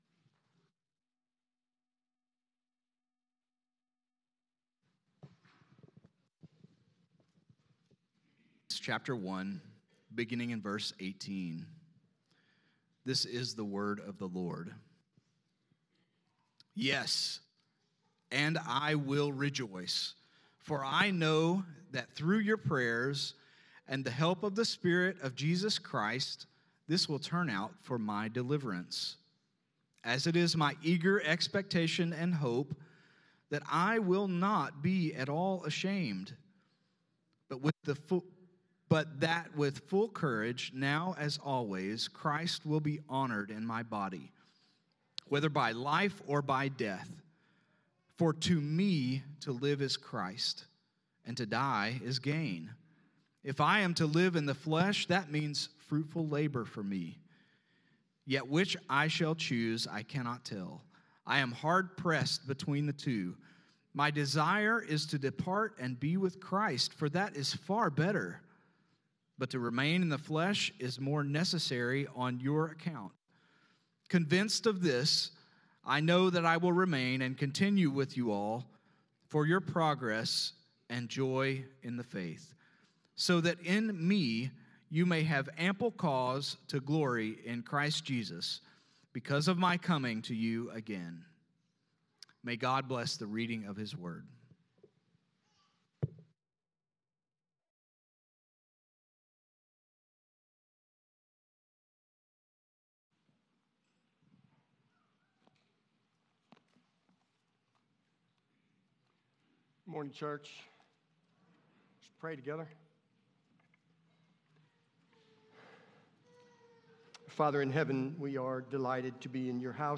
A sermon from the book of Philippians from Oak Park Baptist Church in Jeffersonville, Indiana